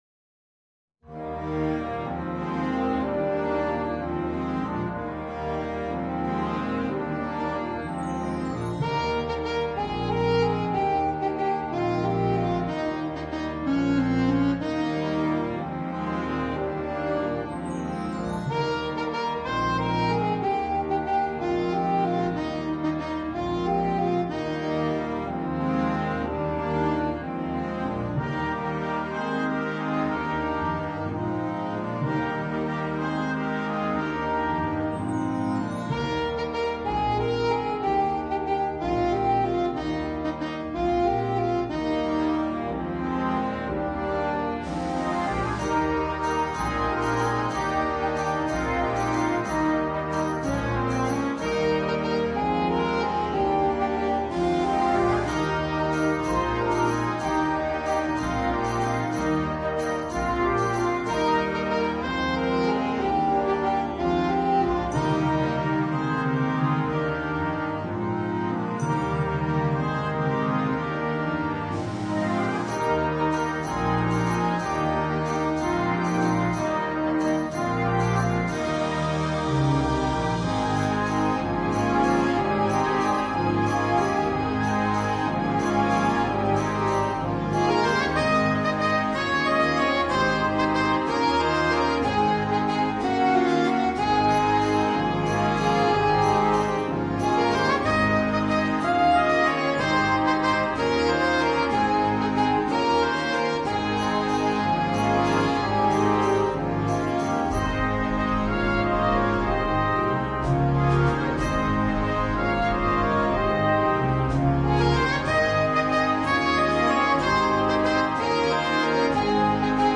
for Alto Sax or Trumpet and Band